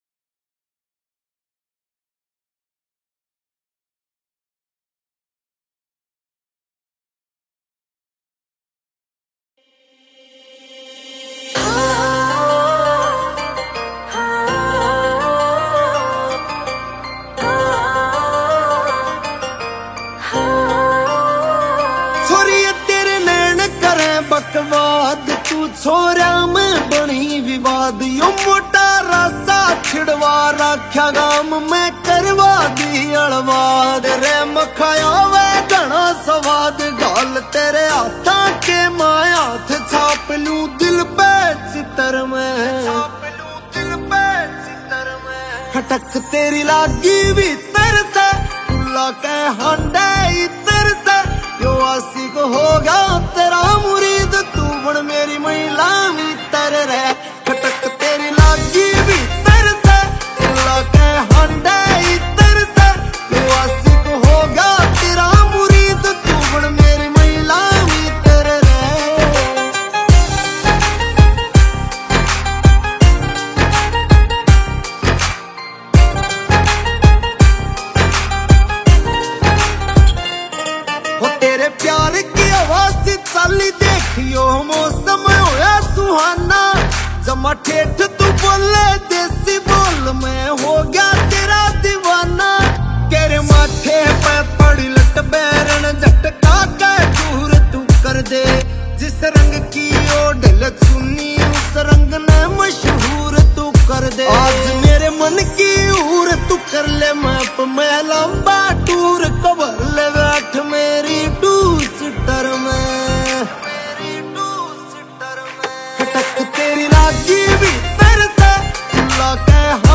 Releted Files Of Haryanvi